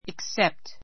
except A2 iksépt イ ク セ プト 前置詞 ～以外は（すべて）, ～を除いて（みんな） He works every day except Sunday.